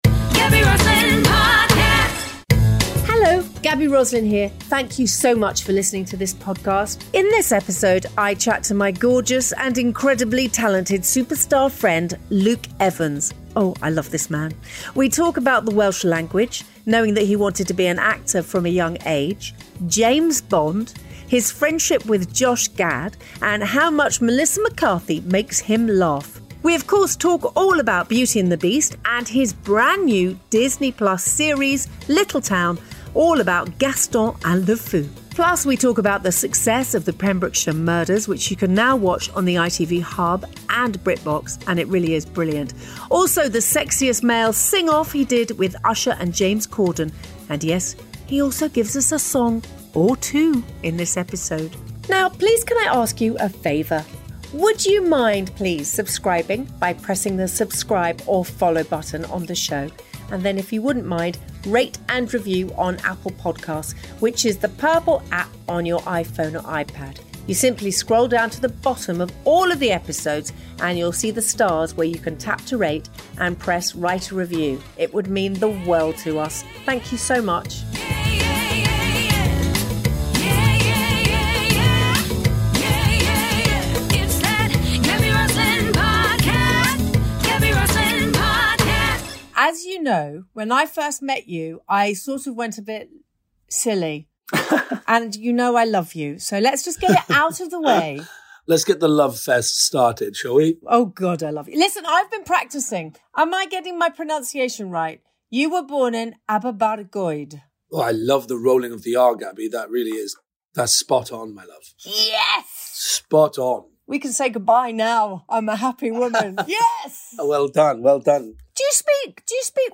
In this episode Gaby chats to the incredibly talented actor & singer Luke Evans. He talks about knowing he wanted to be an actor from a young age, singing in the Welsh language, the James Bond rumour mill and how much he laughed whilst working with Melissa McCarthy.
He divulges what it was like to perform the ‘sexiest male riff-off‘ with Usher and James Corden on The Late Late Show and even gives us a song or two in this episode!